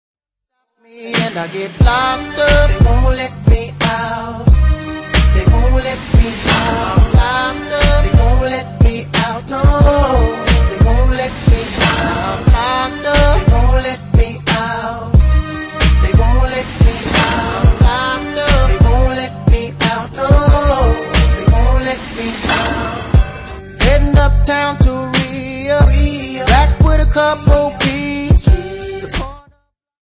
Rap & Hip Hop